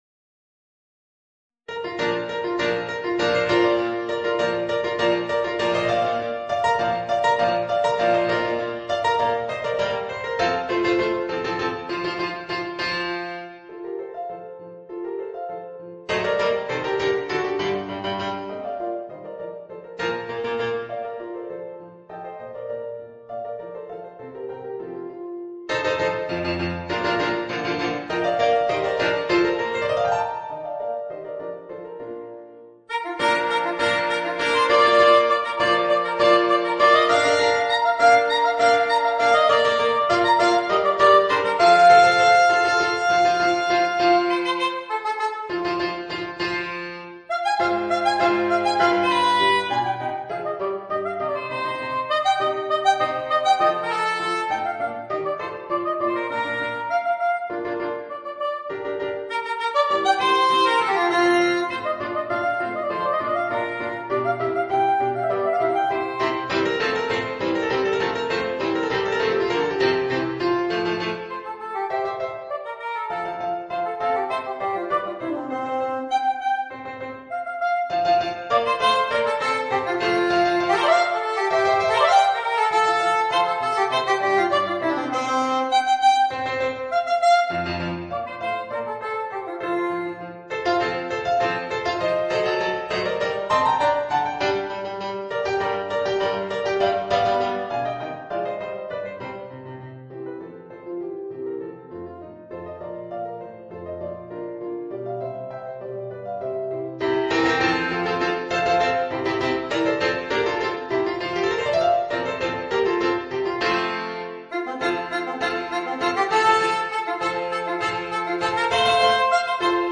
Voicing: Soprano Saxophone and Organ